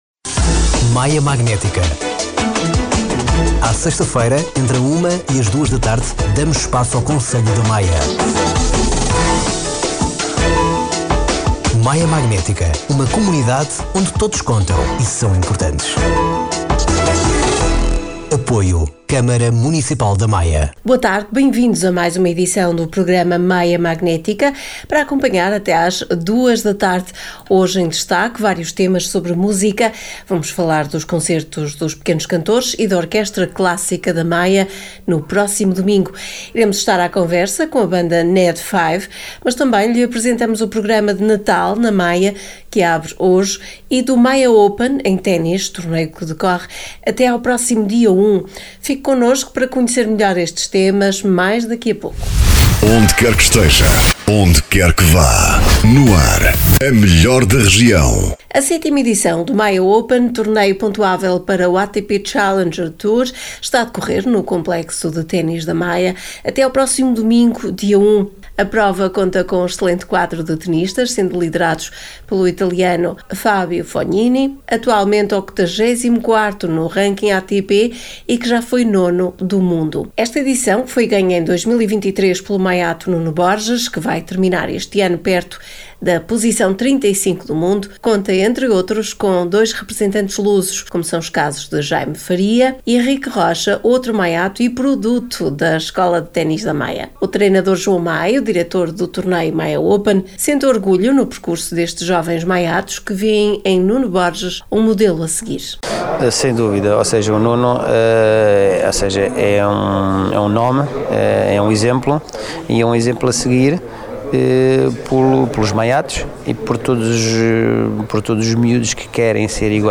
Neste programa estão em destaque vários temas ligados à música: os concertos dos Pequenos Cantores e da Orquestra Clássica da Maia no próximo domingo (dia 1) e a entrevista de divulgação da banda NED5.